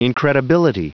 Prononciation du mot incredibility en anglais (fichier audio)
Prononciation du mot : incredibility